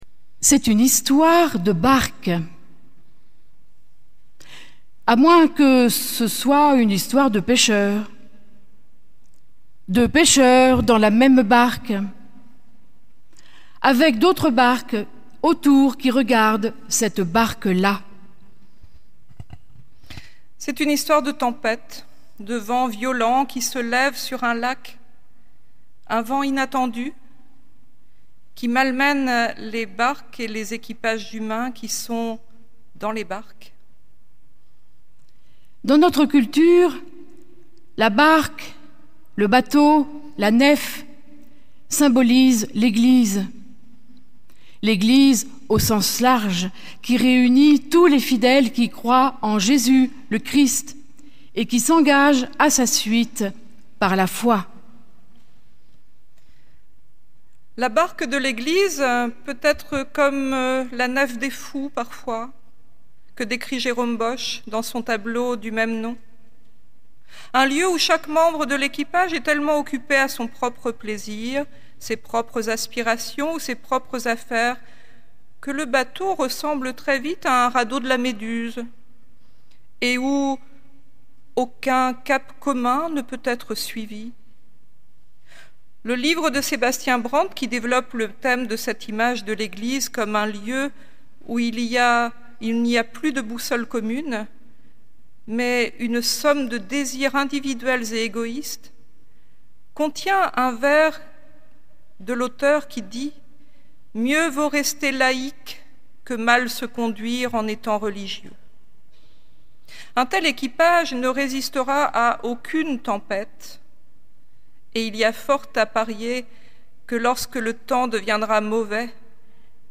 Culte du dimanche 30 juin 2024
Prédication à deux voix